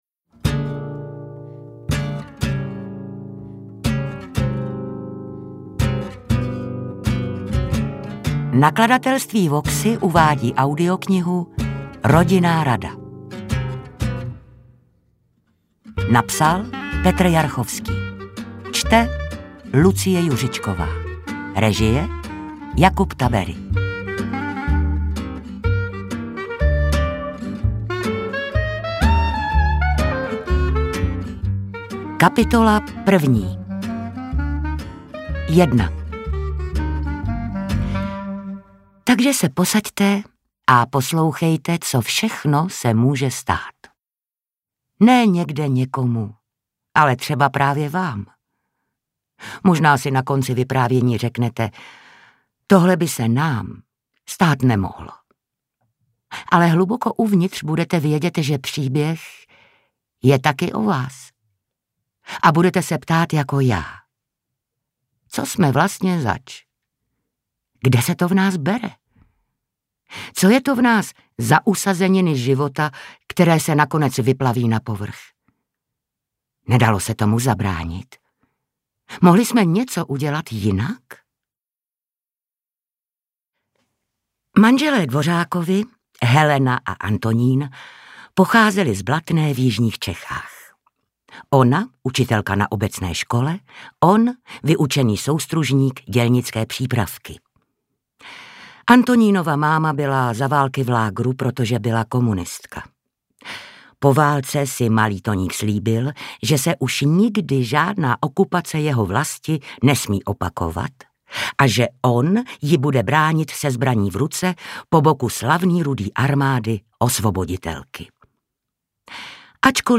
Interpret:  Lucie Juřičková
AudioKniha ke stažení, 20 x mp3, délka 8 hod. 32 min., velikost 466,6 MB, česky